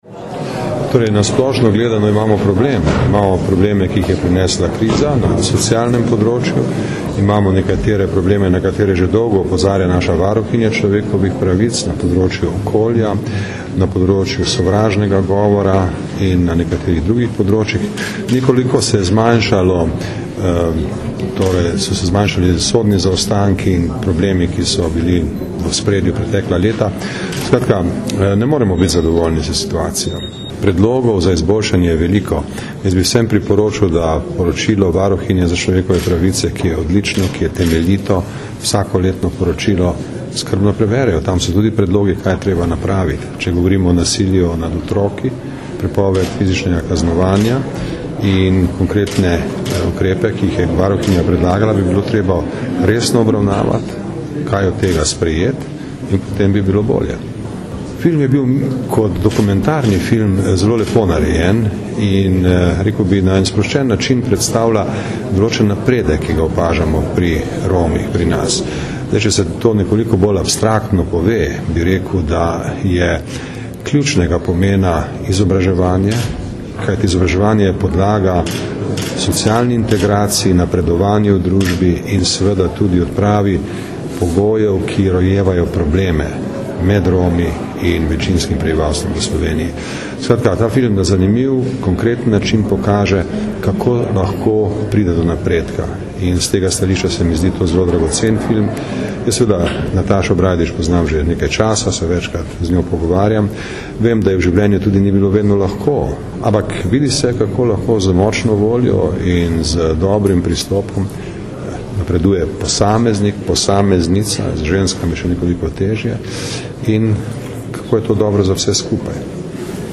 Zvočni posnetek izjave dr. Danila Türka (mp3)